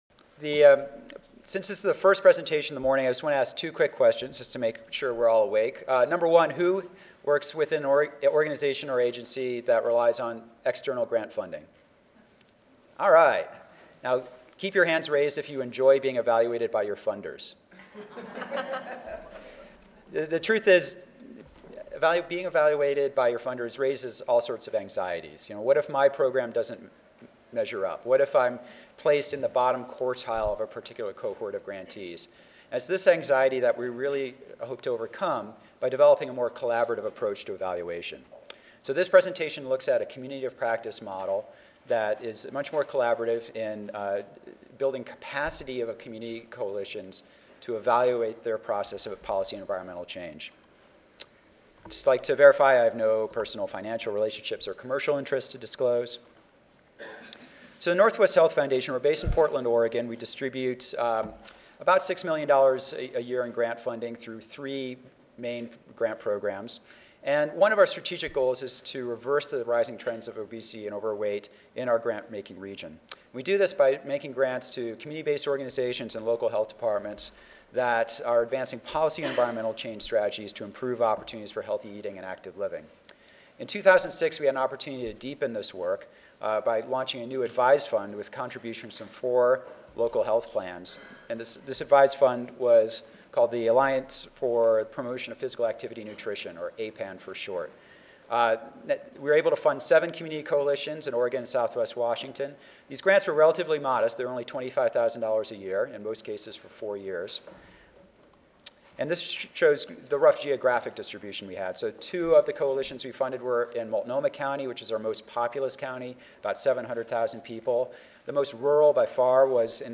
This presentation shares lessons learned from a two-year �Community of Practice� evaluation project involving seven community coalitions in Oregon and southwest Washington advancing policy and environmental changes. This project � titled �Northwest Community Changes� � employed a collaborative model focusing on building coalition capacity to evaluate their process of policy change.